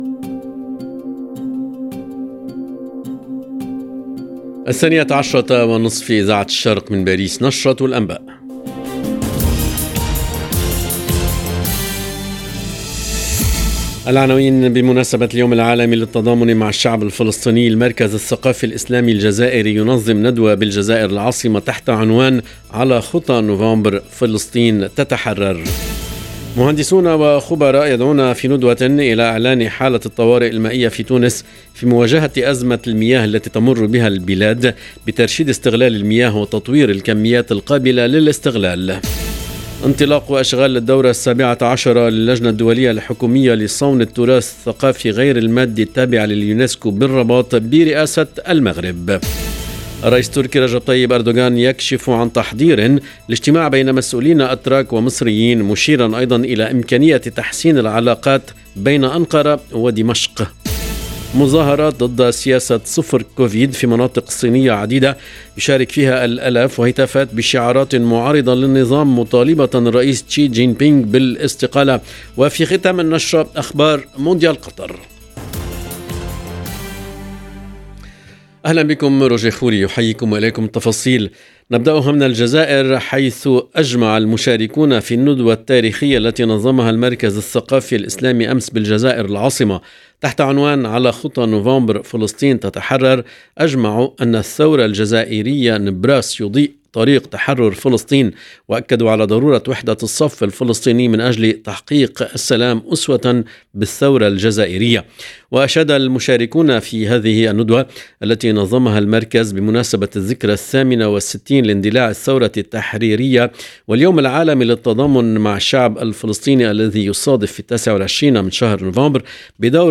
LE JOURNAL EN LANGUE ARABE DE MIDI 30 DU 28/11/22